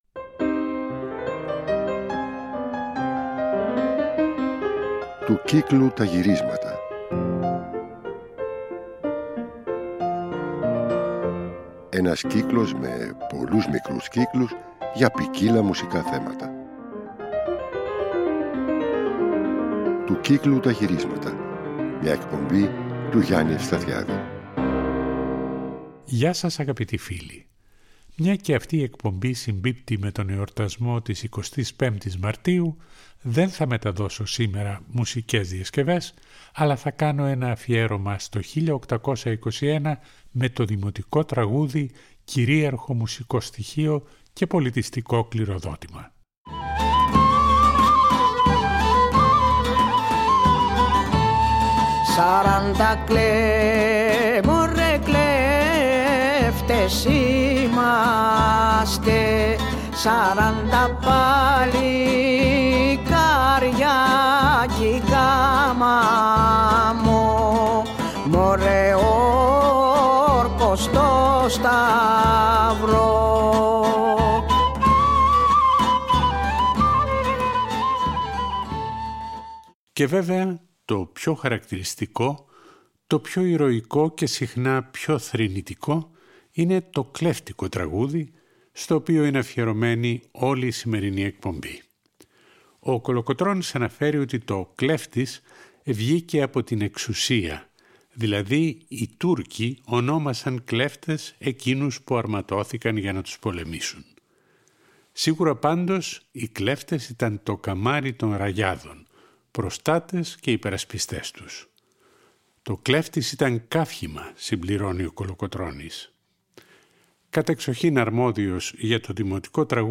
Τέλος, η εκπομπή θα κλείσει με τον περίφημο «Γέρο Δήμο» από τον «Μάρκο Μπότσαρη» του Παύλου Καρρέρ σε ιστορικές εκτελέσεις.